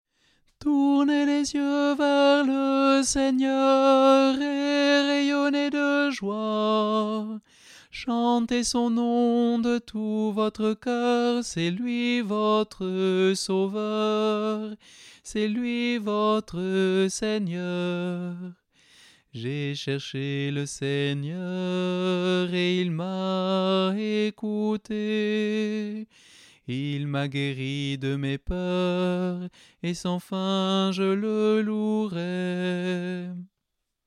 Voix chantée (MP3)COUPLET/REFRAIN
ALTO